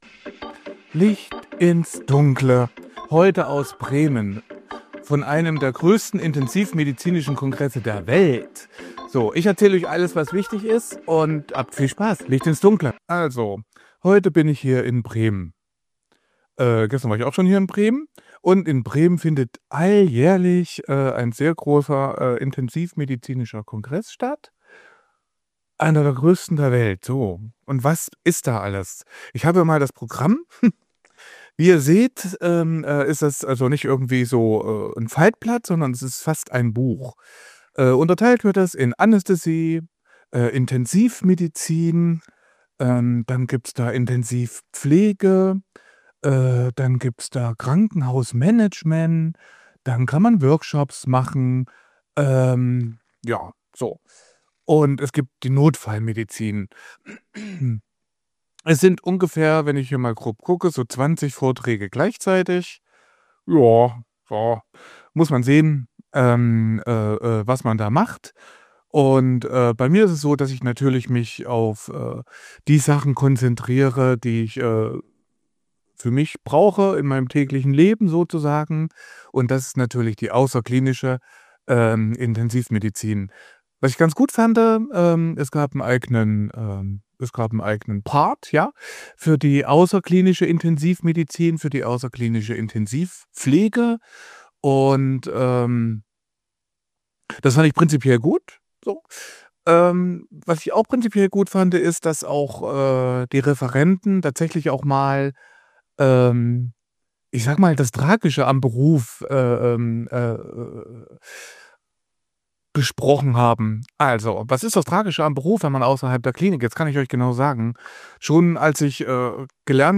In dieser Folge von Licht ins Dunkle berichte ich direkt vom intensivmedizinischen Kongress in Bremen – einem der größten weltweit.